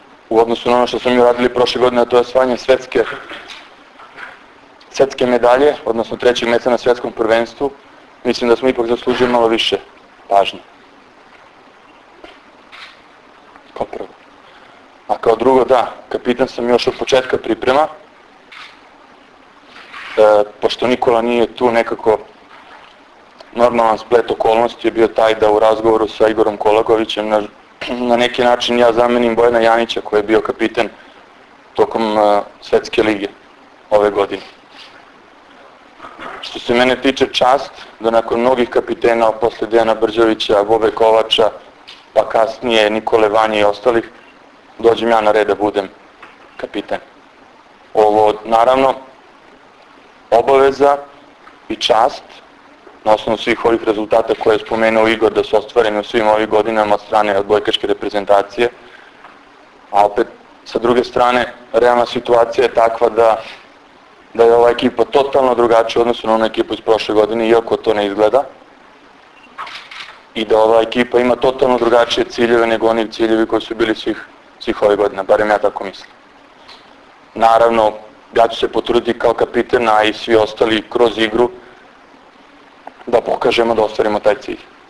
Tim povodom, danas je u beogradskom hotelu “M” održana konferencija za novinare, kojoj su prisustvovali Igor Kolaković, Ivan Miljković, Dragan Stanković i Vlado Petković.
IZJAVA IVANA MILJKOVIĆA 1